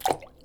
drop_in_lava.wav